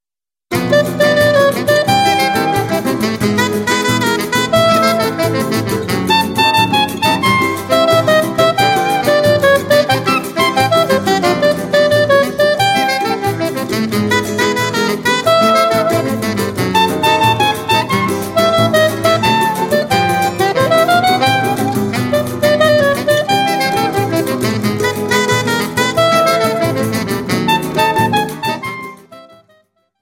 soprano saxophone, tenor saxophone
Choro ensemble (other tracks)